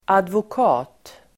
Uttal: [advok'a:t]